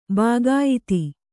♪ bāgāyiti